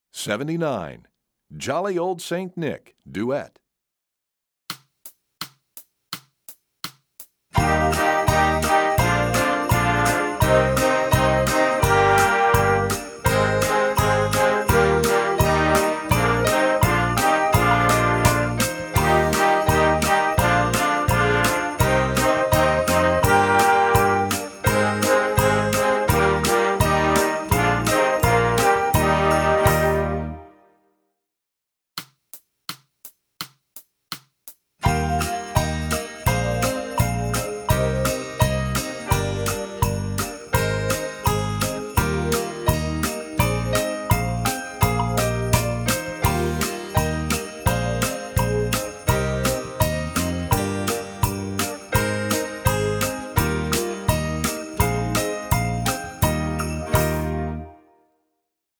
Trombone
Concerto